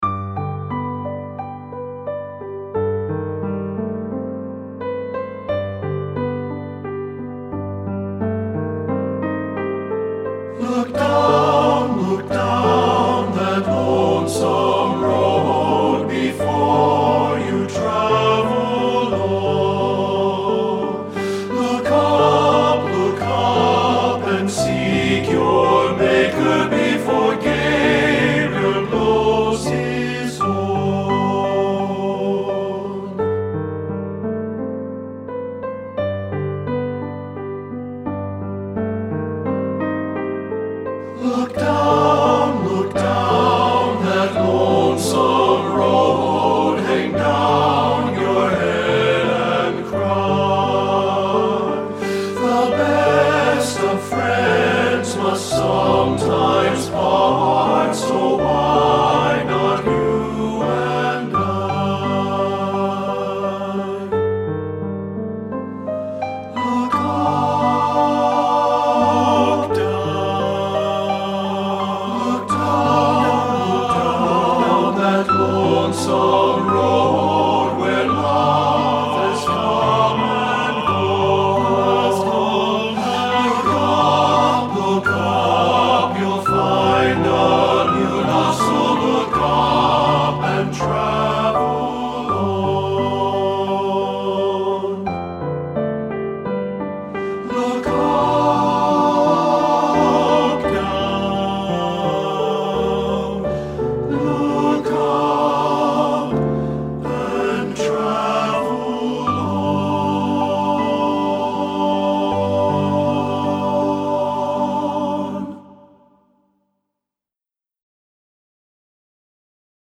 Composer: Spiritual
Voicing: TTB